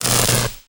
electra_hit1.ogg